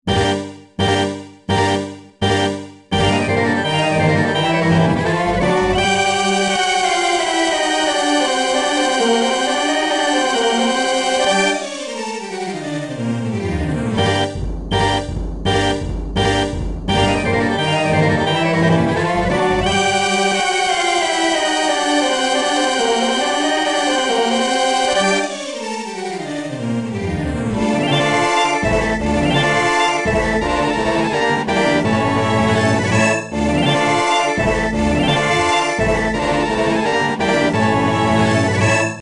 クラシック